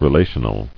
[re·la·tion·al]